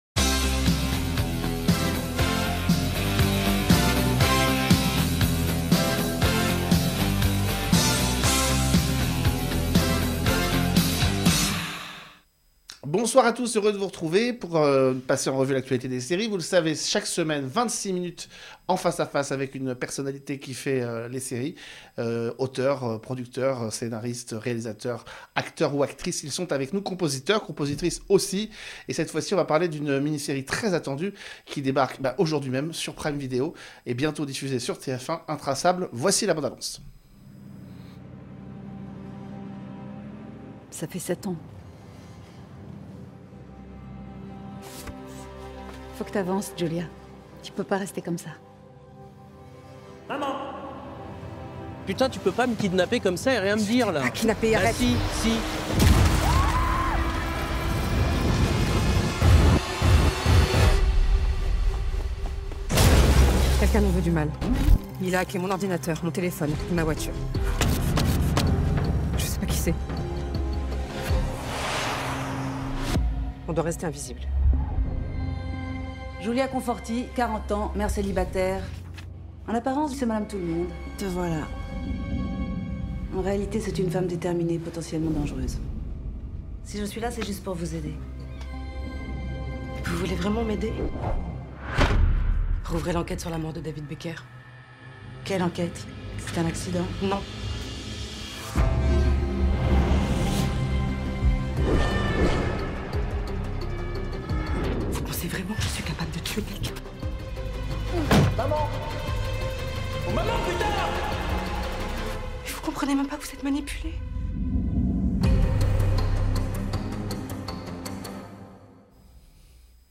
L’invitée : Sofia Essaïdi
A l’occasion de la diffusion sur Prime Vidéo de la série Intraçables, Sofia Essaïdi est l’invitée exceptionnelle de La loi des séries.